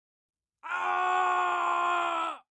Download Famous Screams sound effect for free.
Famous Screams